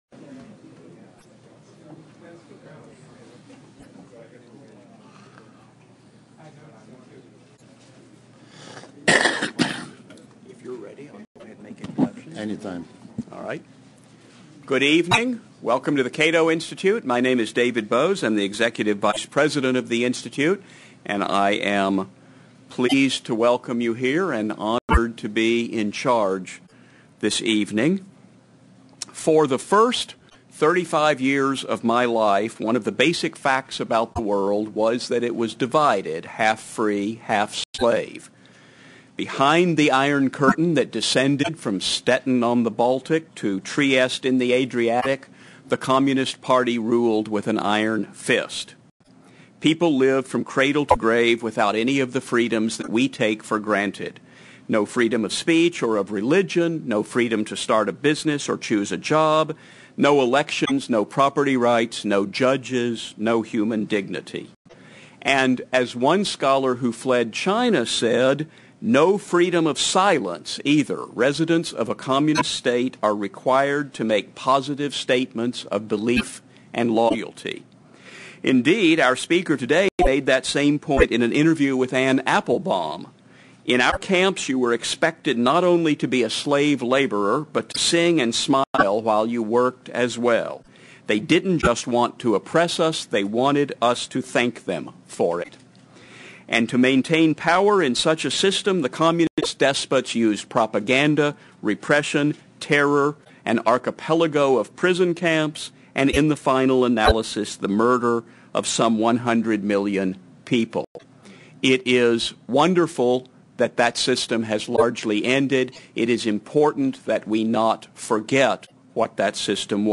Vladimir Bukovsky: The Power of Memory and Acknowledgement - Cato Institute Distinguished Lecture
The Cato Institute 1000 Massachusetts Avenue, NW Washington, DC 20001 Twenty years after the fall of the Berlin Wall, renowned Soviet dissident Vladimir Bukovsky will reflect on the need for Russia to acknowledge the criminal nature of its communist past.